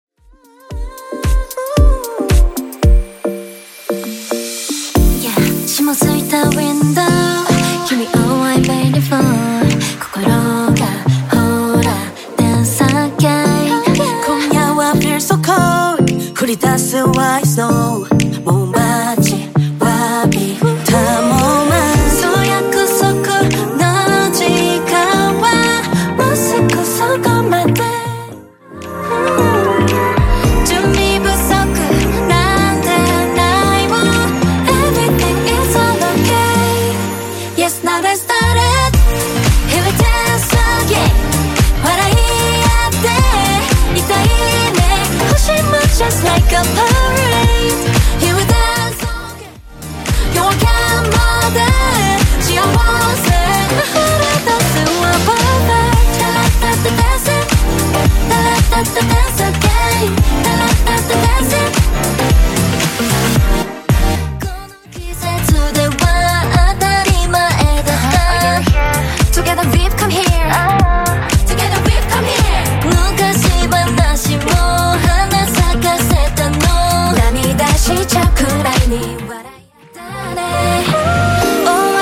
Genre: 70's
BPM: 130